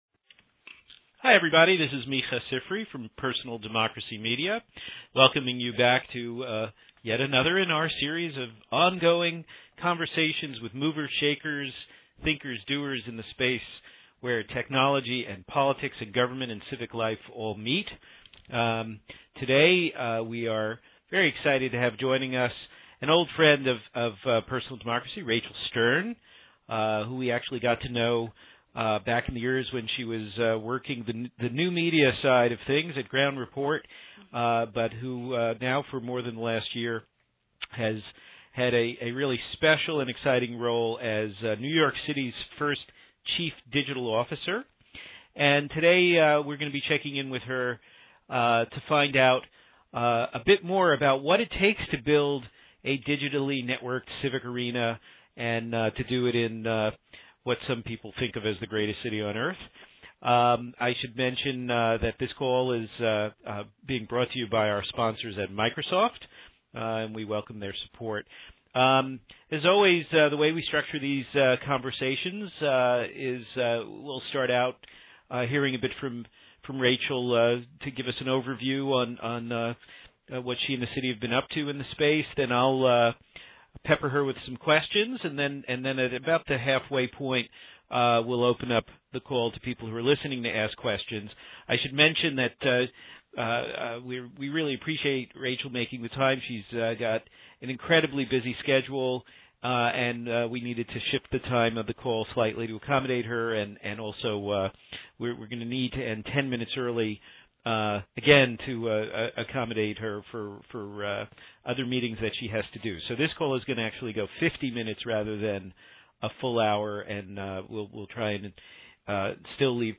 Continuing our ongoing conversation about how cities using open data and embracing open collaboration are fueling the rise of we-government, on this call we checked in with Rachel Sterne, New York City's first-ever chief digital officer, for a progress report.